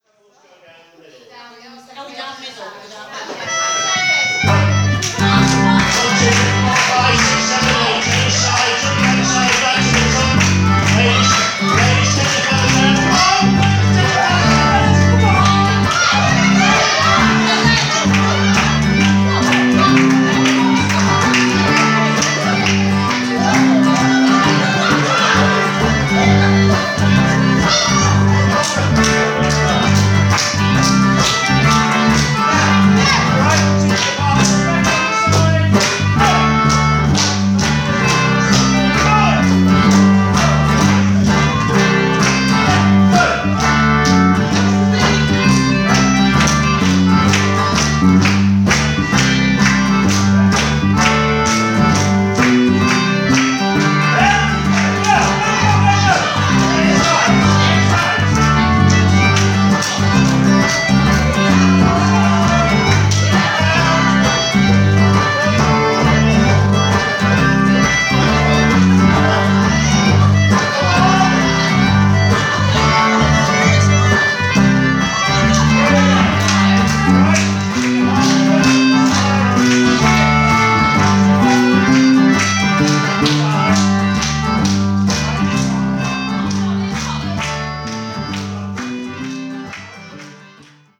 Our style is on the rocky/jazzy side of folk, though we are always sensitive to the audience's preferences on volume level.
Thady You Gander (Hornpipe).mp3